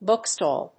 アクセント・音節bóok・stàll